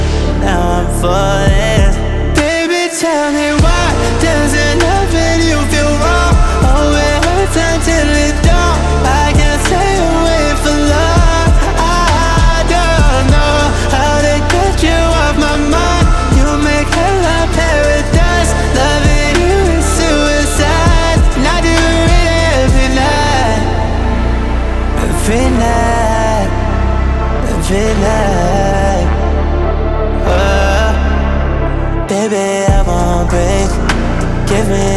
Жанр: Поп музыка / R&B / Соул
Pop, R&B, Soul, Contemporary R&B